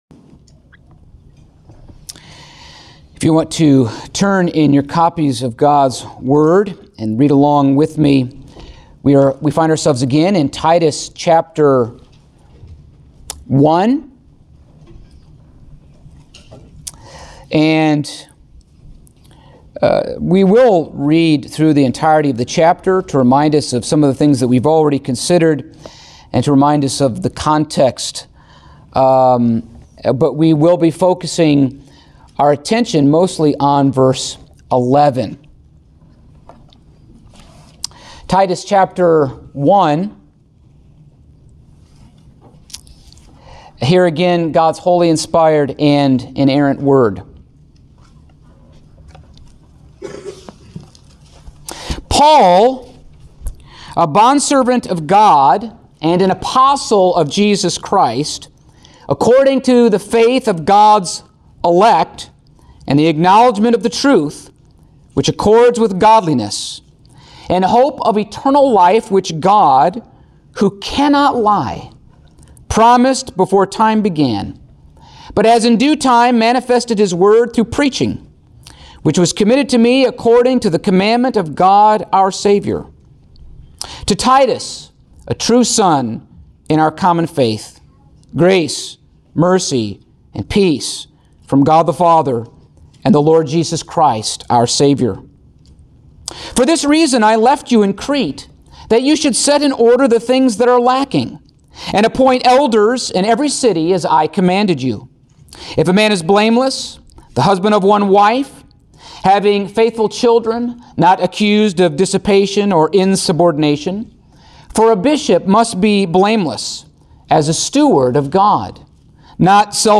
Passage: Titus 1:11 Service Type: Sunday Morning